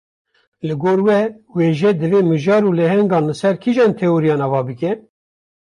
Pronúnciase como (IPA)
/weːˈʒɛ/